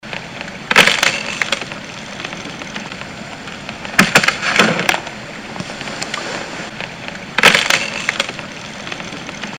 Звуки бобров
Звук бобра, пилящего дерево